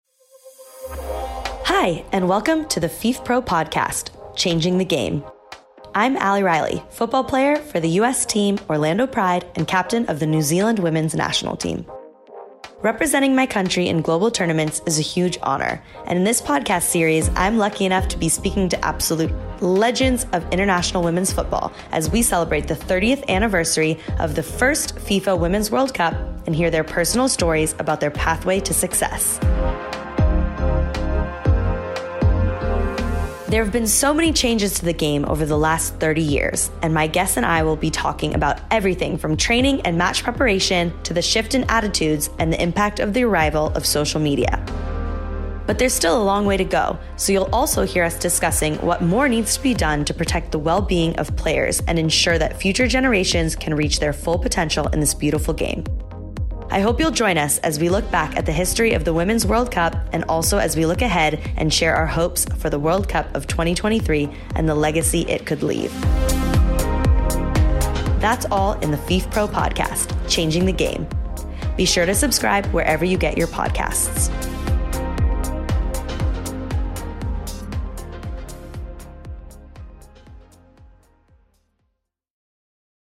Hosted by New Zealand international Ali Riley, the first episode of Changing The Game arrives November 3rd.